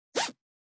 409,ジッパー,チャックの音,洋服関係音,ジー,バリバリ,カチャ,ガチャ,シュッ,パチン,
ジッパー効果音洋服関係